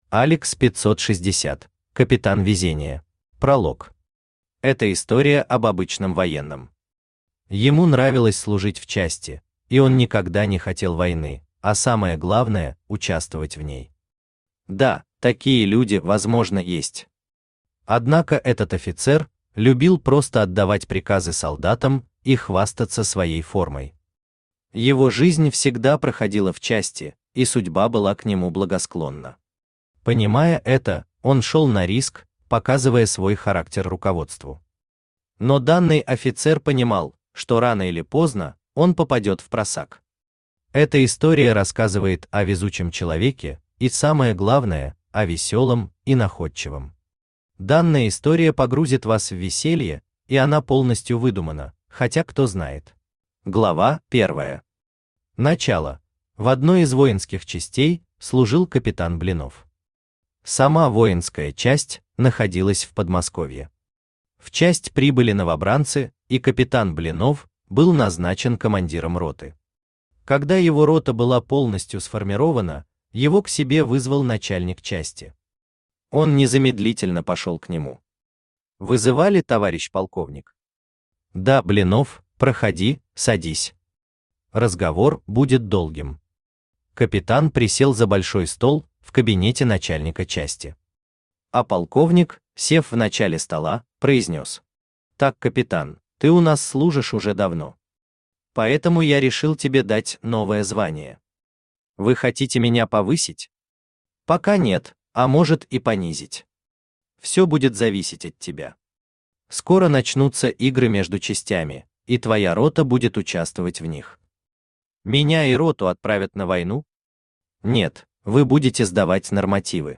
Аудиокнига Капитан везение | Библиотека аудиокниг
Aудиокнига Капитан везение Автор ALEX 560 Читает аудиокнигу Авточтец ЛитРес.